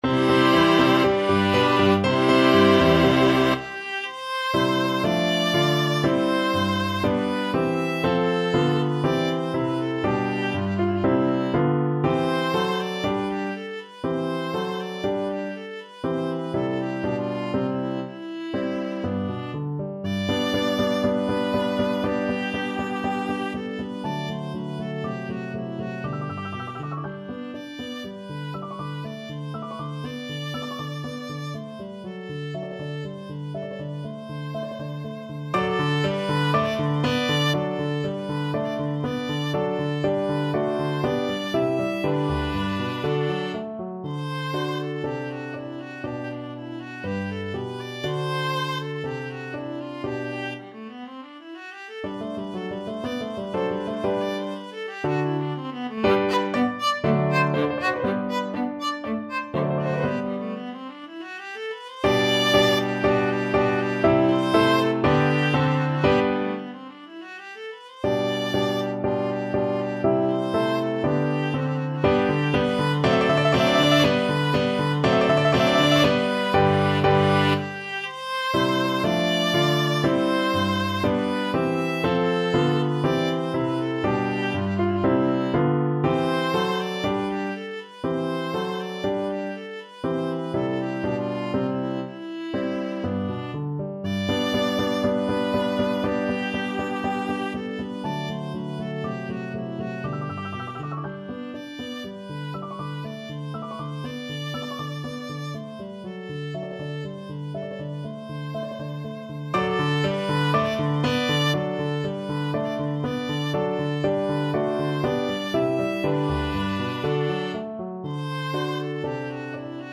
Viola
C major (Sounding Pitch) (View more C major Music for Viola )
~ = 100 Allegro (View more music marked Allegro)
4/4 (View more 4/4 Music)
C4-G6
Classical (View more Classical Viola Music)